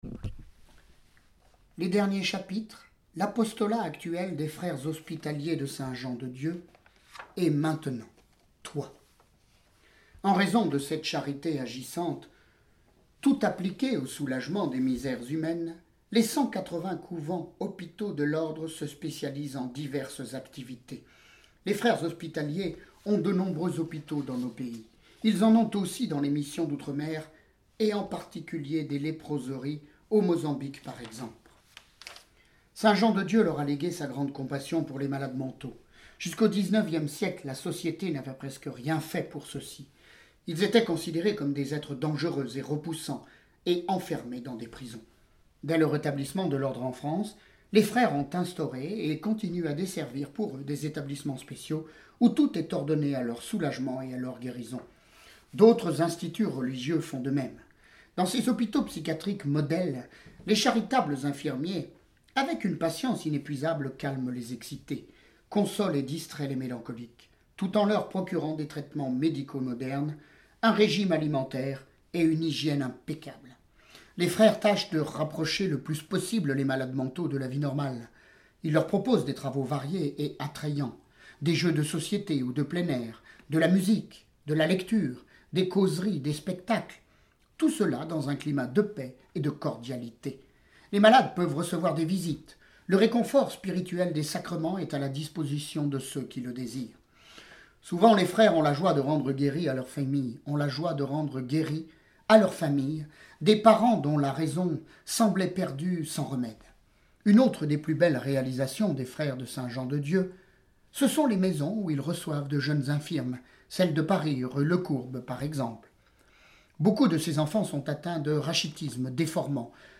Lecture de vies de Saints et Saintes